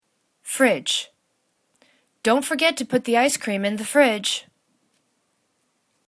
fridge    /fridj/ [C]